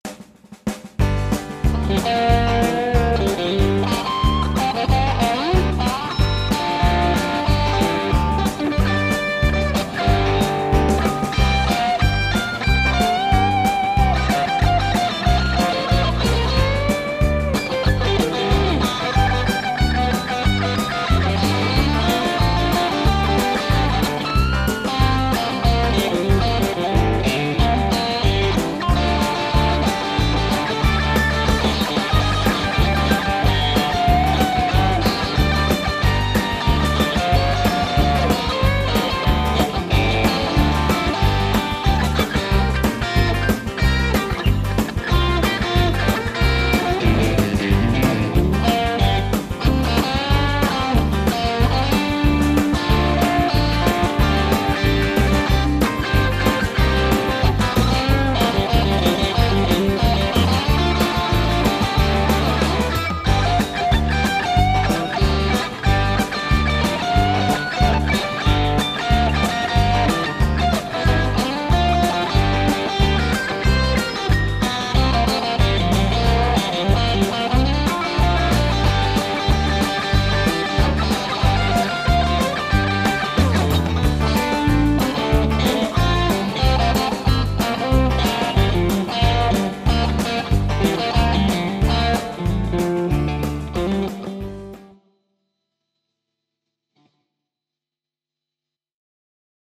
-kaikki rootsmusiikista ja sen soittamisesta pitävät ja kaikkien soitinten taitajat (eli saa vetää millä haluaa) saavat osallistua.
-kun osallistut, soita soolo annetun taustan päälle ja pistä linkki tähän threadiin.